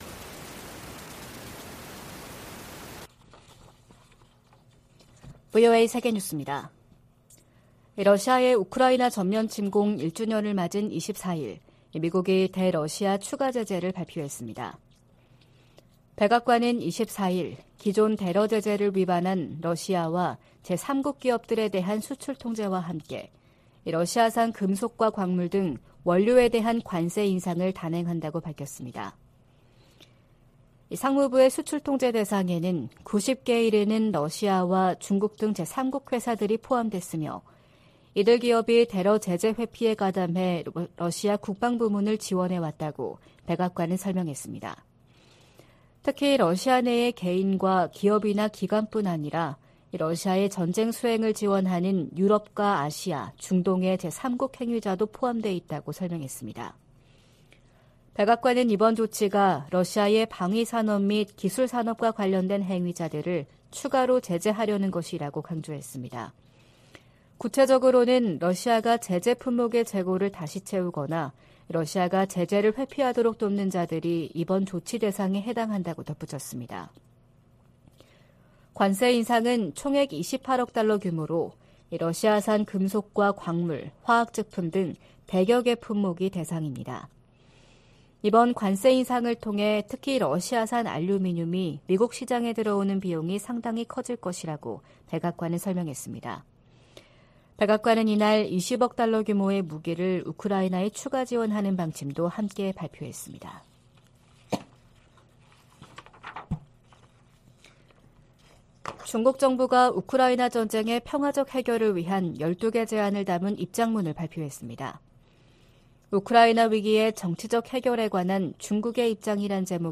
VOA 한국어 '출발 뉴스 쇼', 2023년 2월 25일 방송입니다. 북한은 미국이 연합훈련 등 적대적이며 도발적인 관행을 계속 이어가면 선전포고로 간주될 수 있다고 밝혔습니다. 미 국방부는 미한 두 나라가 22일 펜타곤에서 제8차 양국 확장억제 운용연습(DSC TTX)을 실시했다고 밝혔습니다. 미 민주당의 브래드 셔먼 하원의원이 한국전쟁 종전선언을 비롯한 ‘한반도 평화’ 조치를 담은 법안을 다음 주 재발의할 예정입니다.